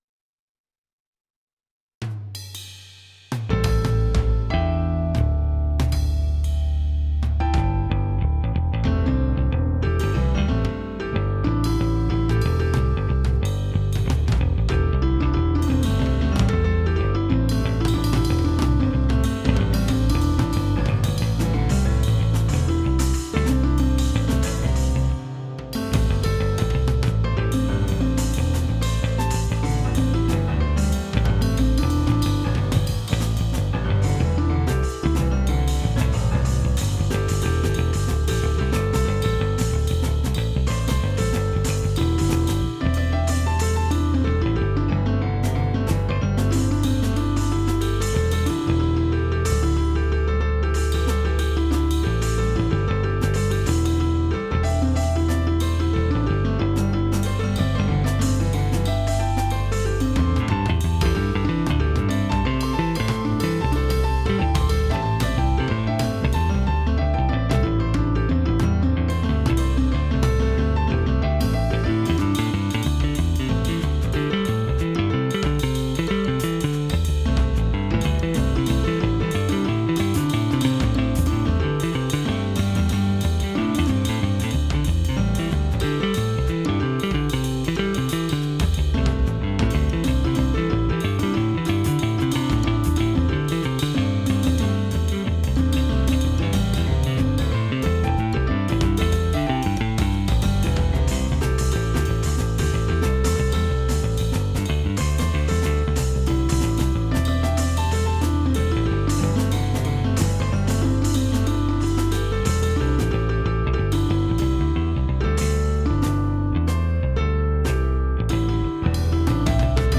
The following are direct recordings of VST instruments rendering a real-time MIDI output stream from µO: